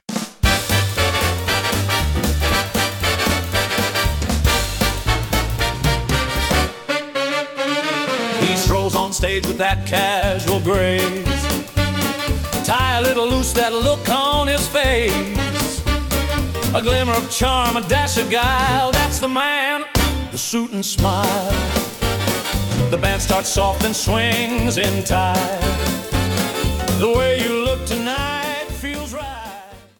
The Man In Suit And Smile (Orchestra Swing Version))